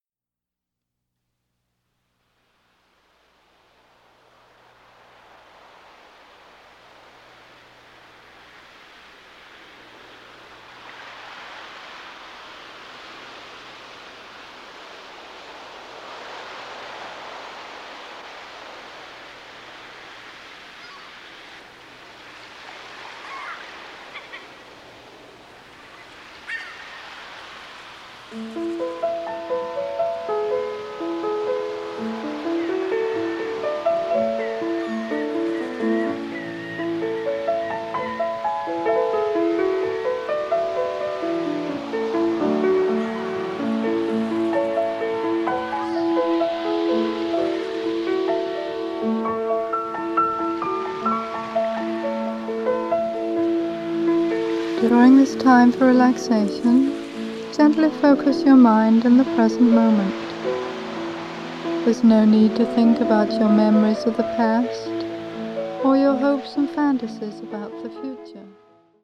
Seasons for Healing: Summer (Guided Meditation)